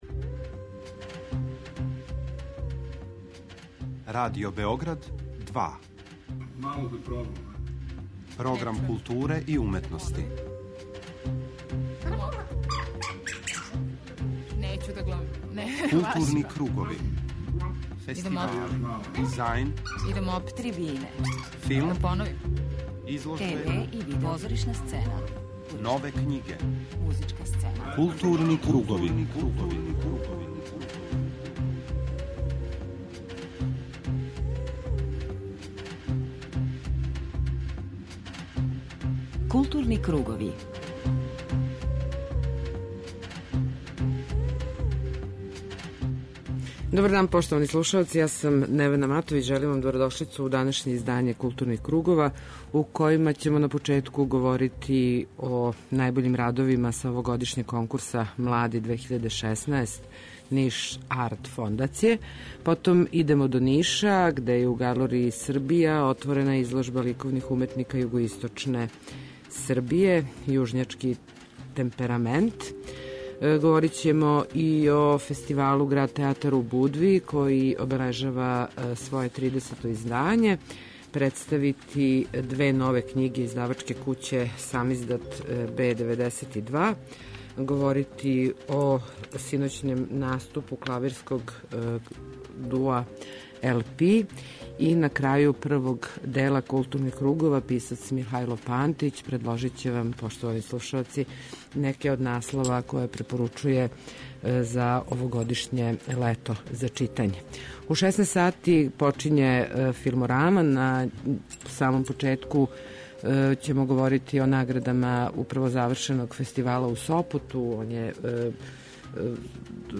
У данашњој Филморами , редитељ и глумац Никола Којо говори о изазовима које му је донела улога редитеља.